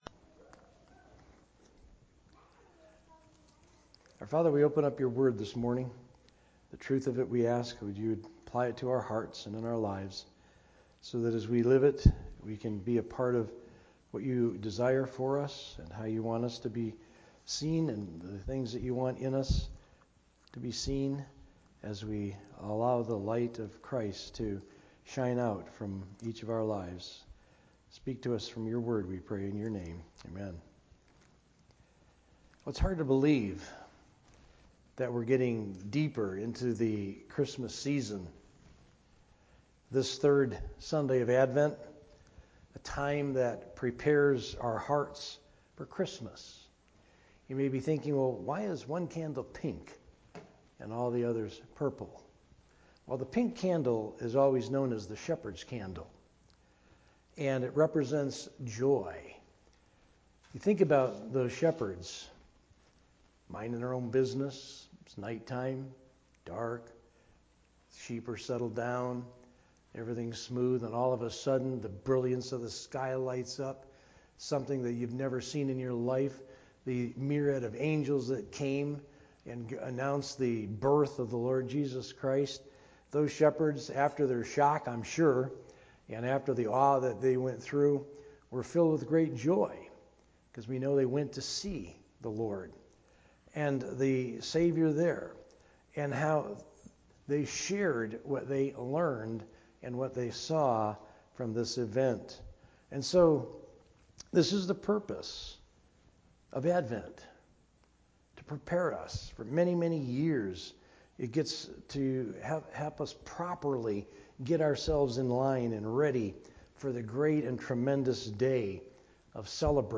From Series: "Sunday Morning - 11:00"
Sermon